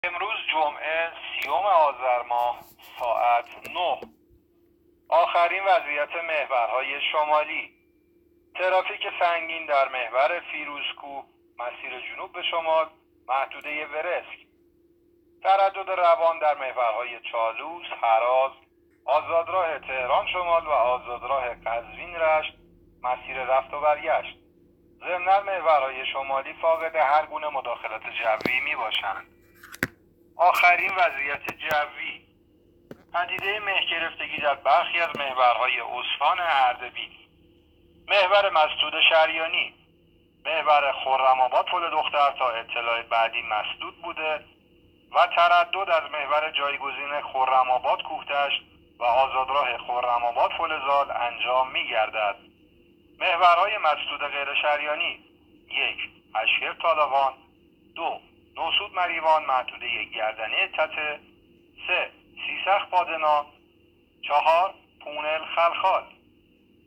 گزارش رادیو اینترنتی از آخرین وضعیت ترافیکی جاده‌ها تا ساعت ۹ سی‌ام آذر؛